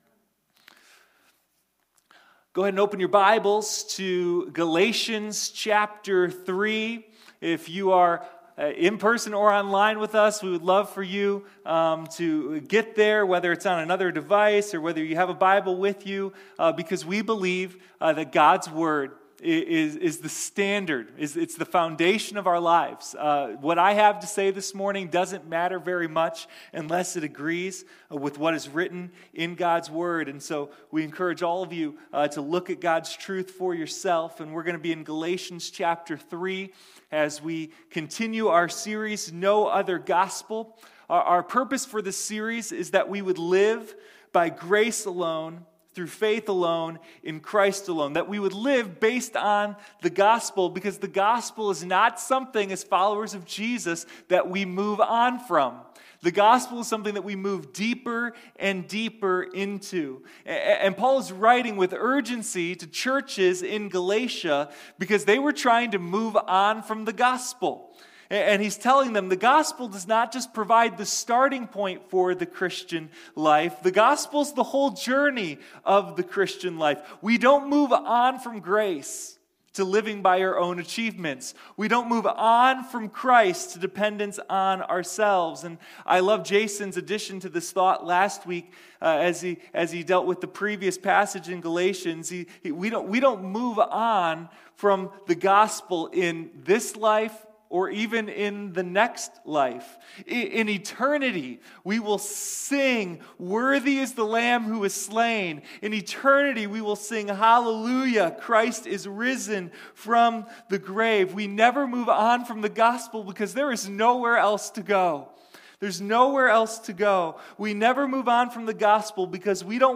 Sunday Morning No Other Gospel: A Study in Galatians